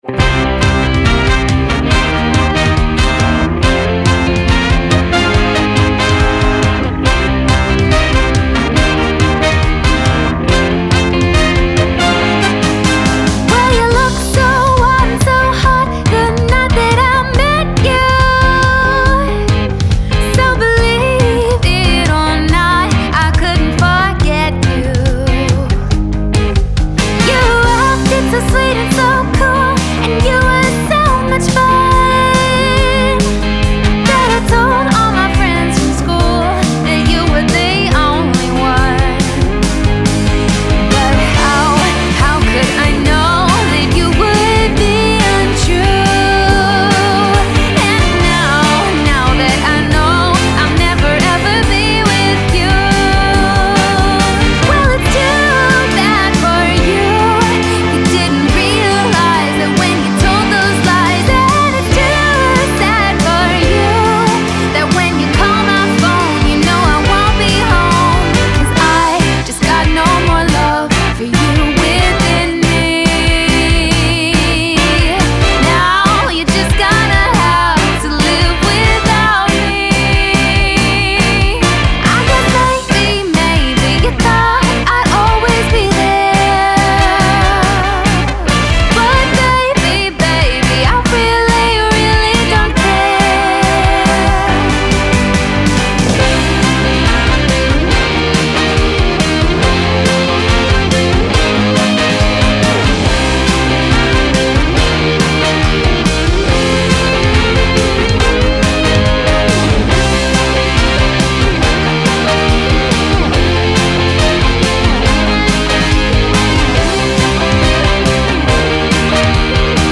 and a Custom Horn Section.
as well as a great female singer.